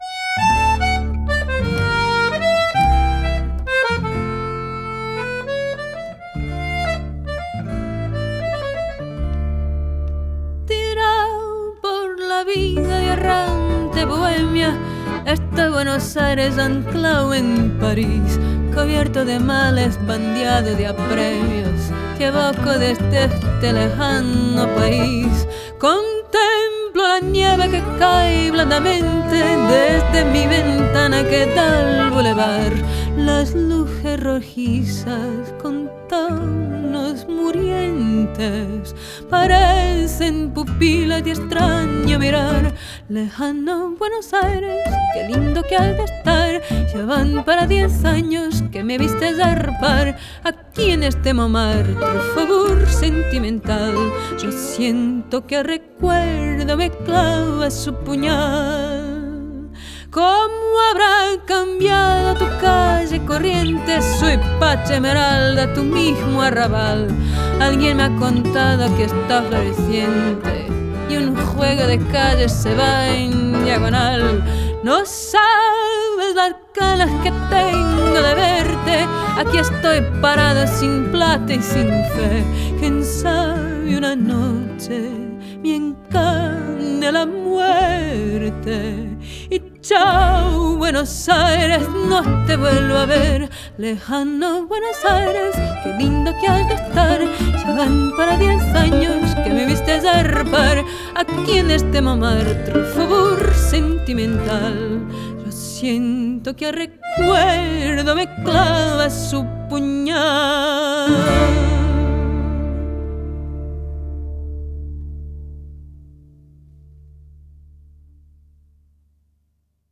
Portuguese Guitar
Double bass
Accordion